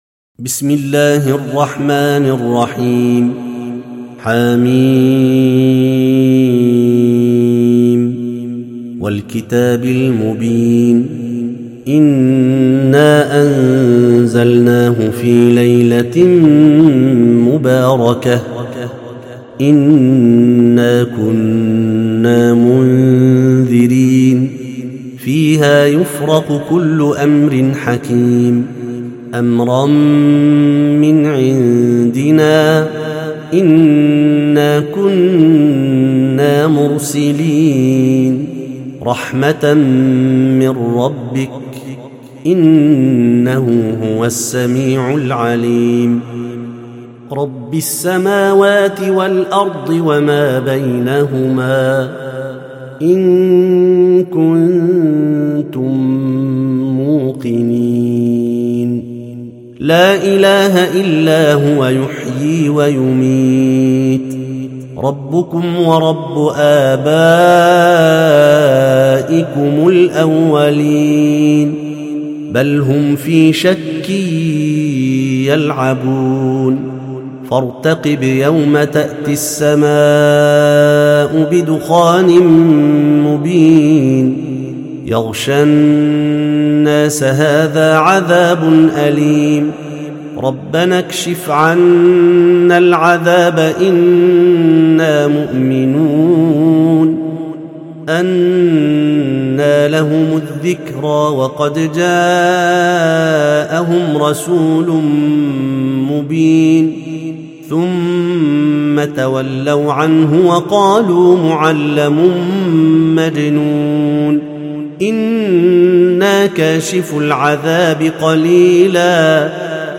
سورة الدخان - المصحف المرتل (برواية حفص عن عاصم)